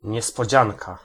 Ääntäminen
Ääntäminen France Tuntematon aksentti: IPA: /e.tɔn.mɑ̃/ Haettu sana löytyi näillä lähdekielillä: ranska Käännös Ääninäyte Substantiivit 1. niespodzianka {f} Suku: m .